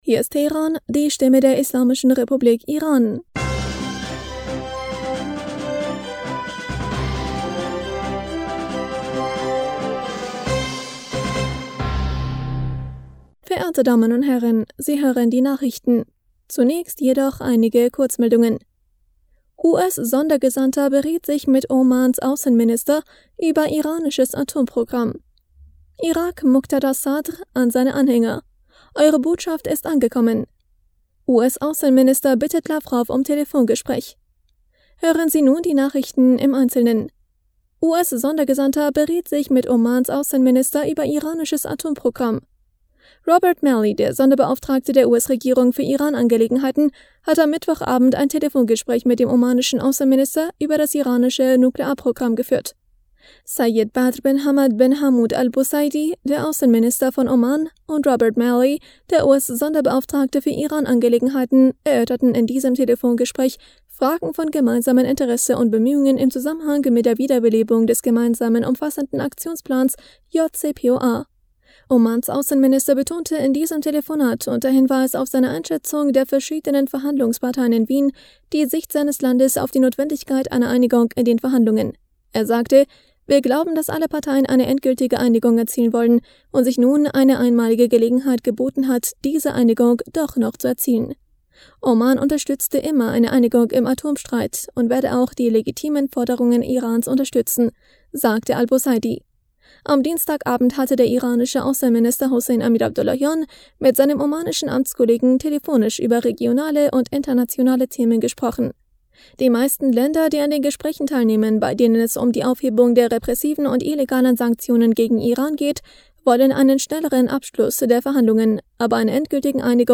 Nachrichten vom 28. Juli 2022